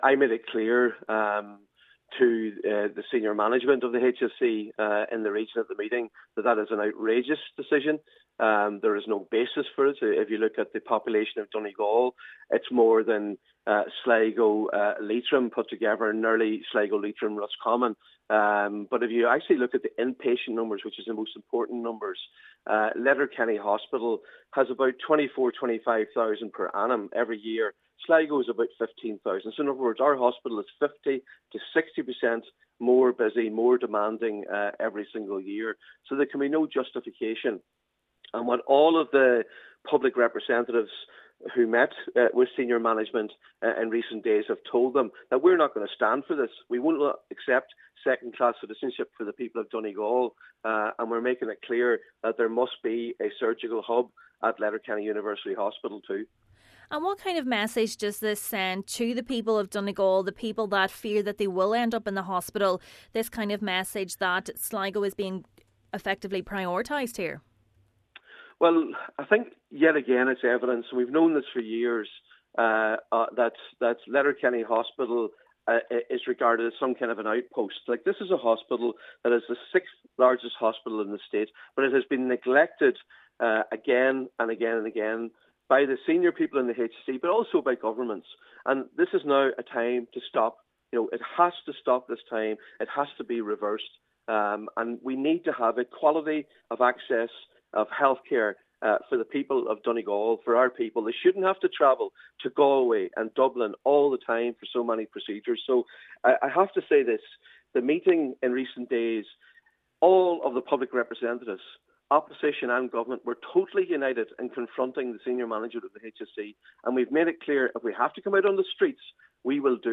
Donegal Deputy Padraig MacLochlainn says under no circumstances can the need for a surgical hub in Letterkenny be overlooked: